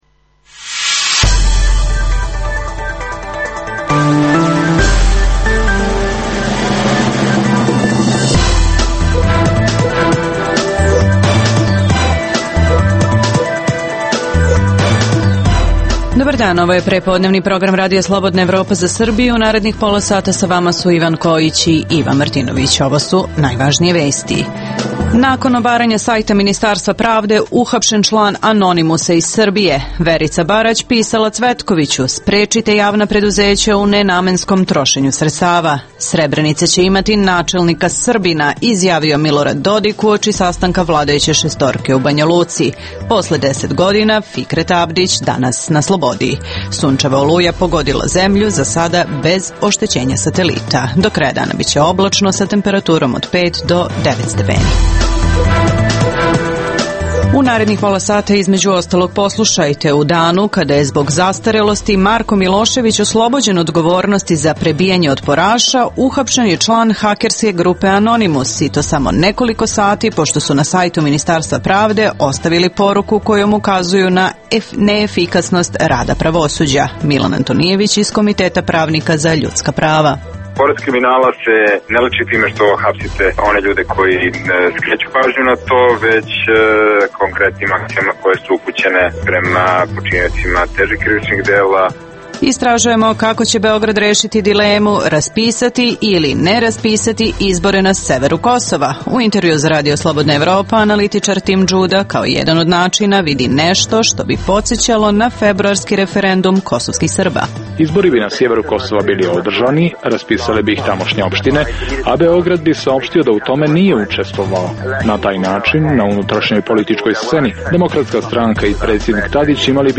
- Kako će Beograd rešiti dilemu raspisati ili ne izbore na severu Kosova. U intervjuu za RSE o tome govori analitičar Tim Judah.